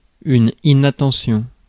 Ääntäminen
IPA: [i.na.tɑ̃.sjɔ̃]